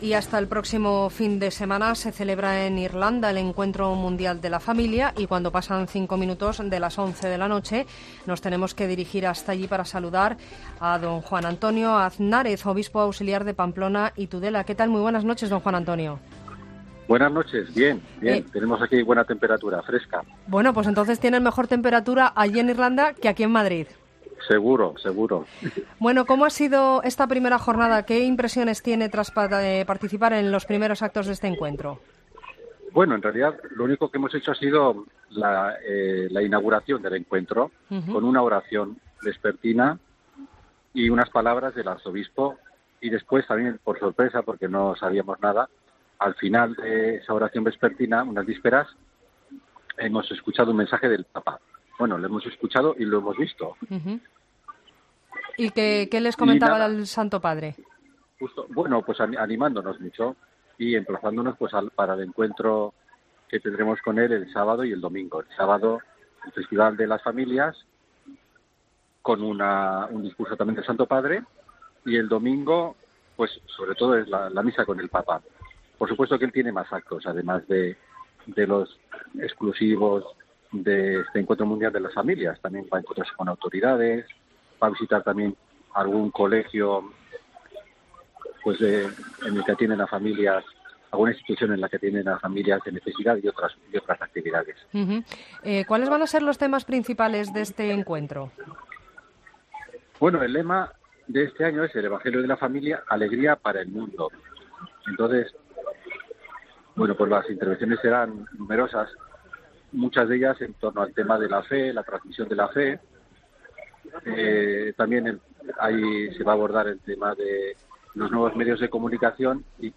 Entrevista a Juan Antonio Aznárez, obispo auxiliar de Pamplona y Tudela